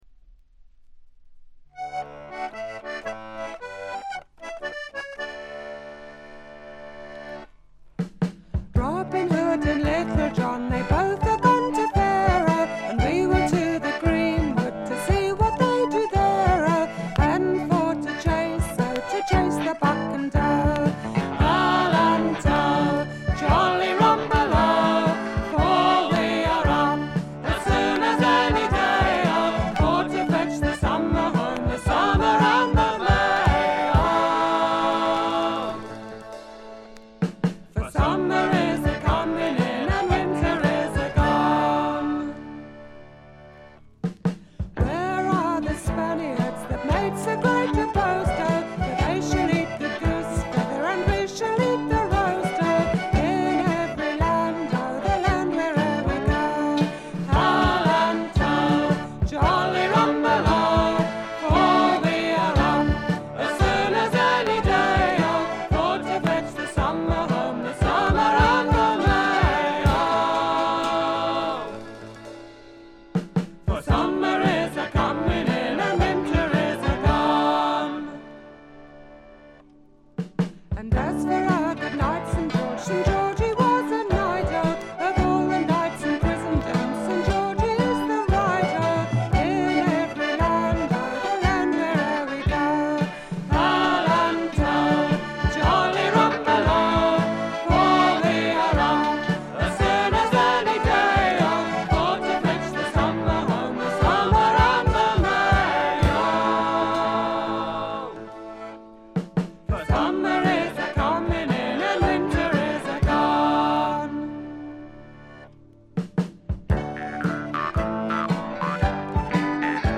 B4の途中でチリプチ、終了の直前直後に大きめのプツ音。
エレクトリック・トラッド最高峰の一枚。
試聴曲は現品からの取り込み音源です。